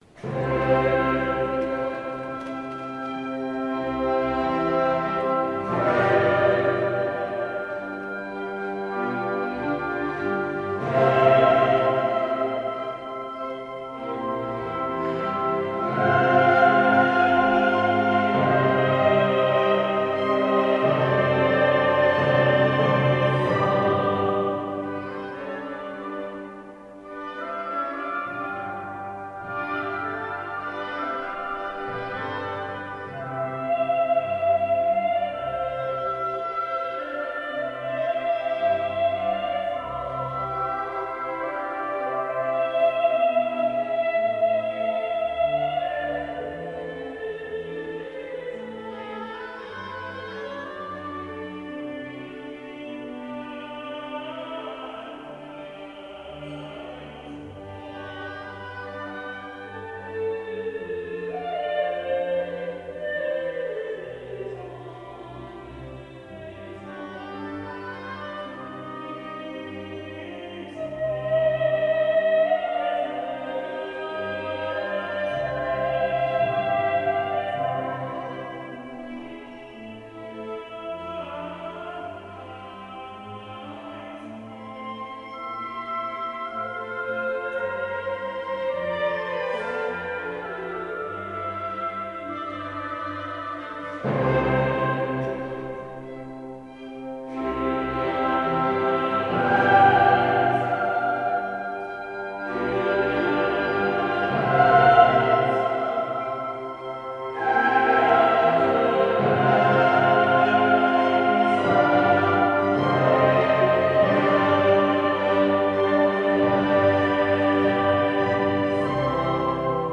Choir and Orchestra
Style: Classical
Audio: Cantores Carmeli Linz (chorus)